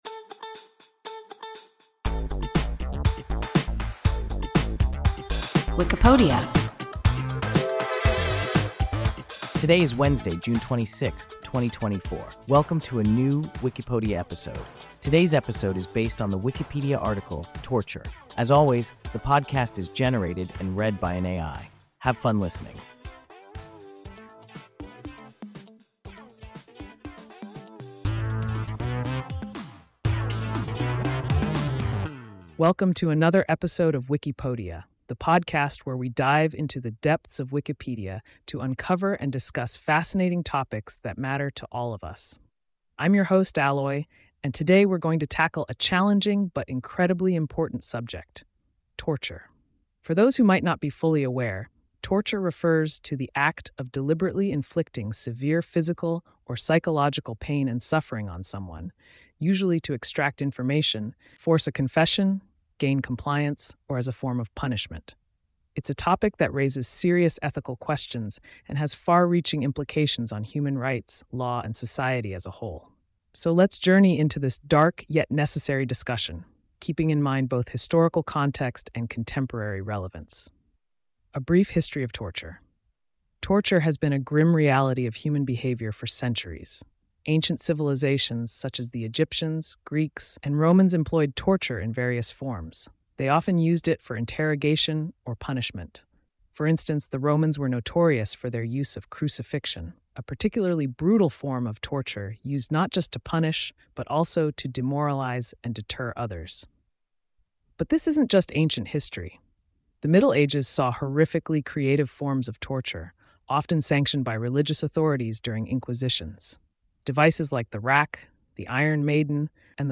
Torture – WIKIPODIA – ein KI Podcast